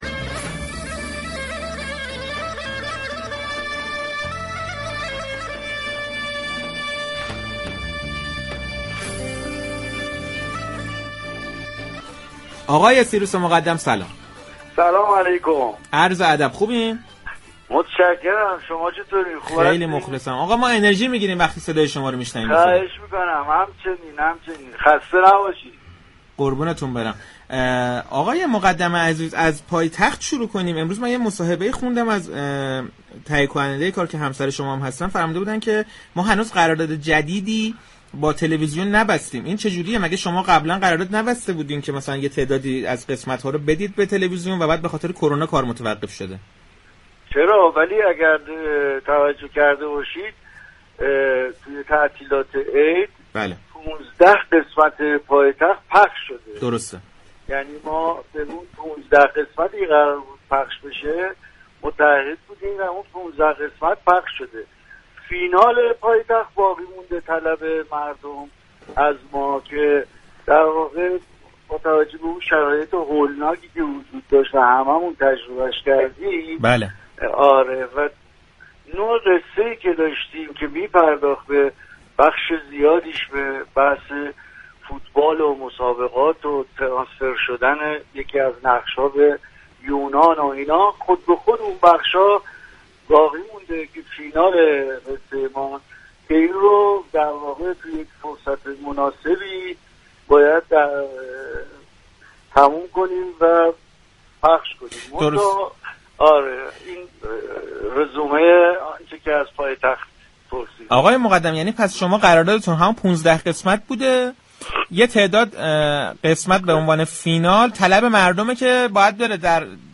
سیروس مقدم كه در شرایط كرونا 15 قسمت پایتخت را كارگردانی كرده و برای نمایش به تلویزیون ارسال كرد در صحنه‌ی رادیو تهران اعلام كرد، هنوز قراردادی برای ساخت قسمتهای پایانی این سریال با سازمان صدا و سیما بسته نشده است.